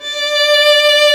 Index of /90_sSampleCDs/Roland - String Master Series/STR_Violin 1-3vb/STR_Vln3 _ marc
STR VLN3 D 4.wav